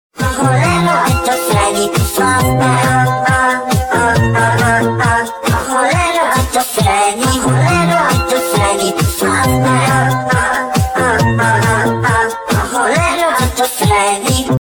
cow-faz-bear-made-with-Voicemod-technology-timestrech-1-timestrech.mp3